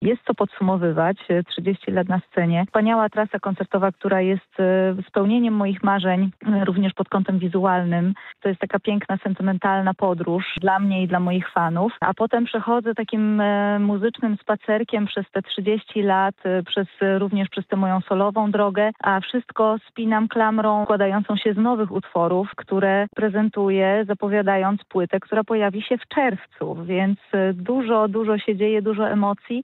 Jak mówi Radiu Lublin Anna Wyszkoni, ten cykl występów to sentymentalna podróż i dla niej i dla jej fanów.